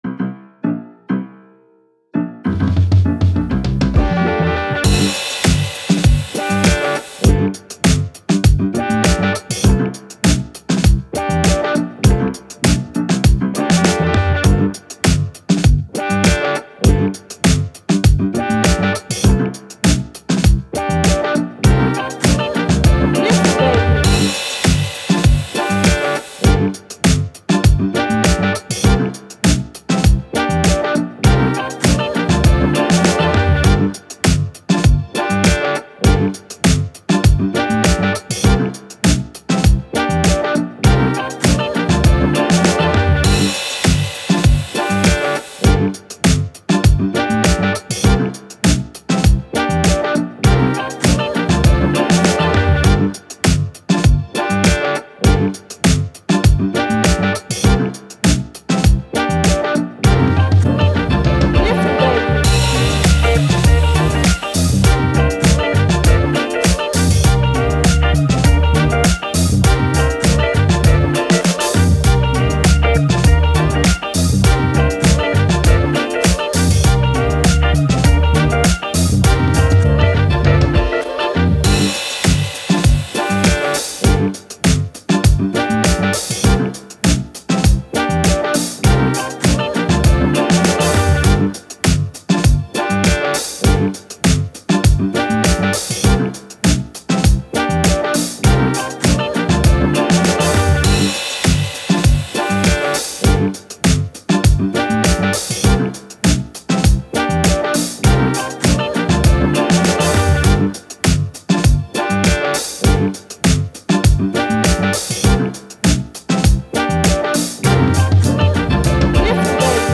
Funk, Jazz, Hip Hop, Positive, Bright, Uplifting